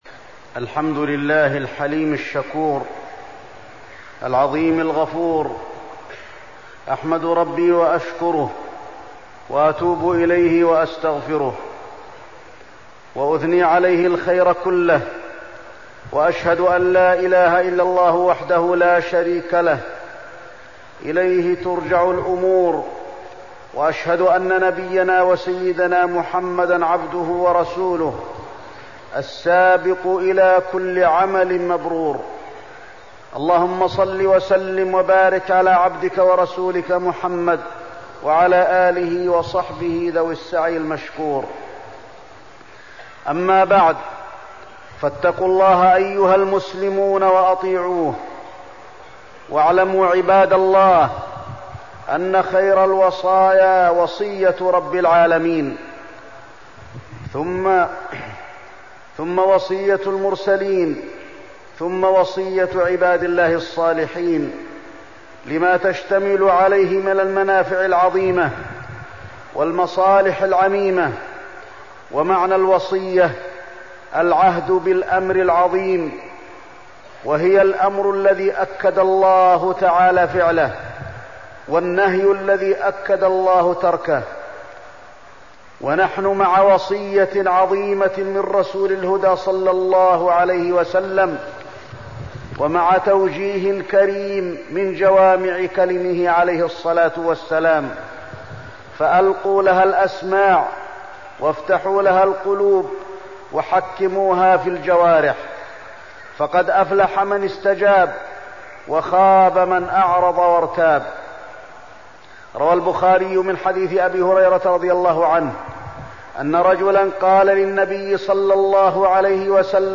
تاريخ النشر ٦ ربيع الثاني ١٤١٦ هـ المكان: المسجد النبوي الشيخ: فضيلة الشيخ د. علي بن عبدالرحمن الحذيفي فضيلة الشيخ د. علي بن عبدالرحمن الحذيفي النهي عن الغضب